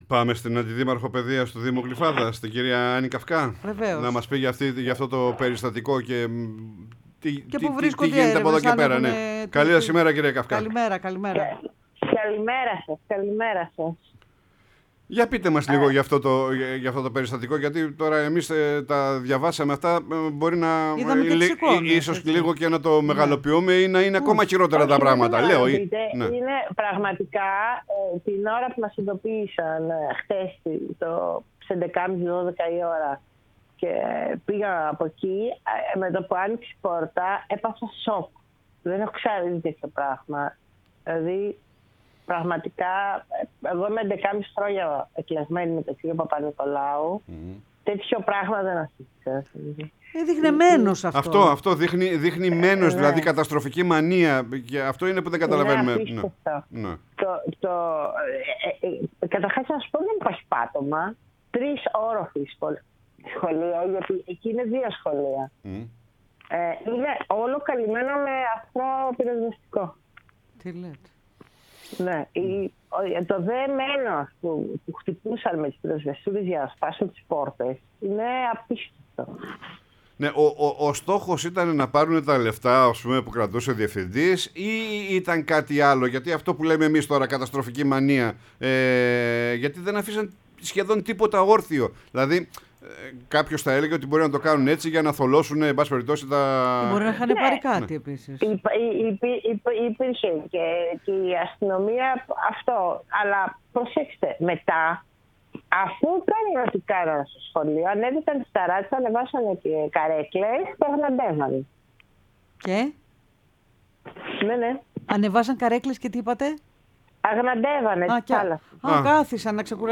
Η Άννυ Καυκά Αντιδήμαρχος παιδείας Δήμου Γλυφάδας, μίλησε στην εκπομπή «Πρωινή Παρέα»